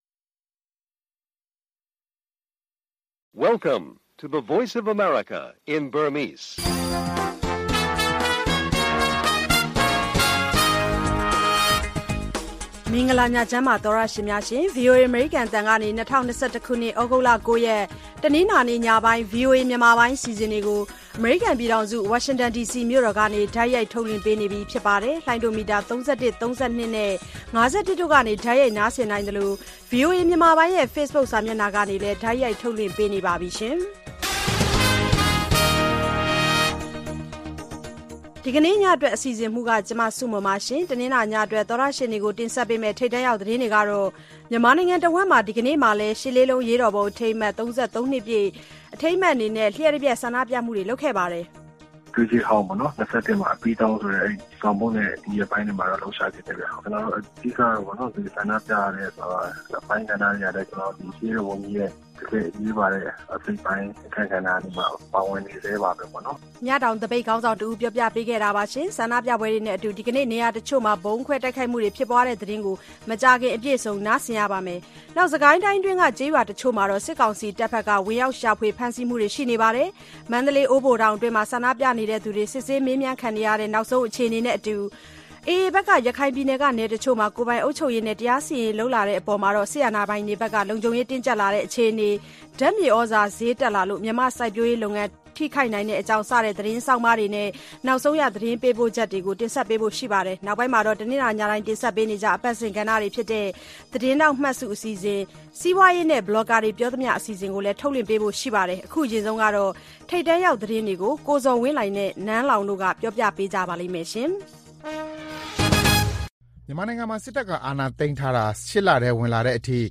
VOA ရေဒီယိုညပိုင်း ၉း၀၀-၁၀း၀၀ တိုက်ရိုက်ထုတ်လွှင့်မှု(သြဂုတ်၉၊၂၀၂၁)